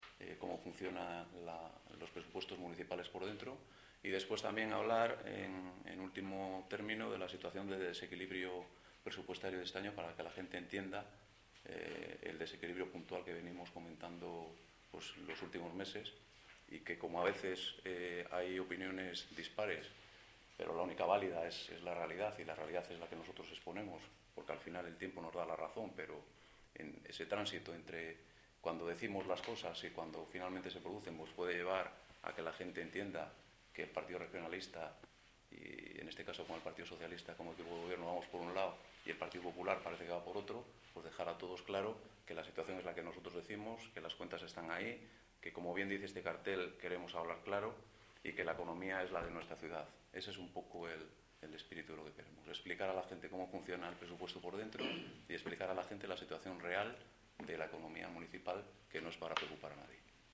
Pedro P. Noriega, concejal de Econom?a y Hacienda